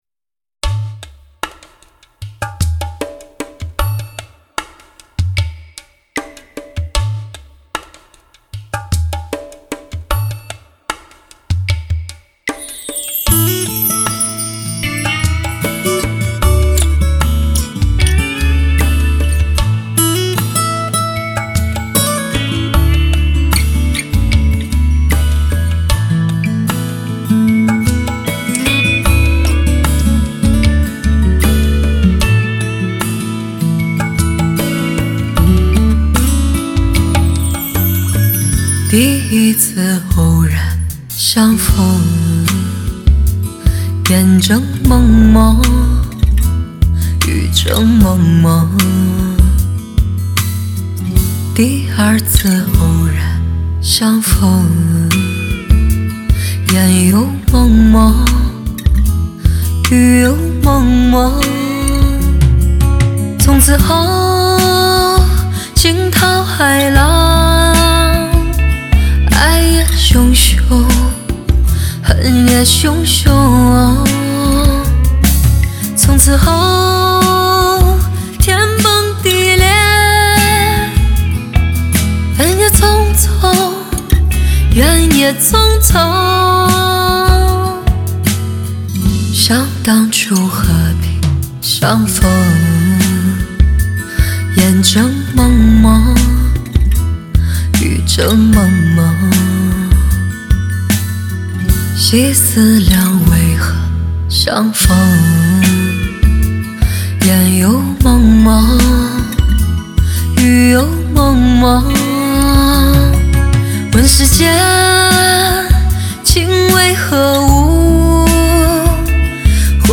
音乐类型：华语流行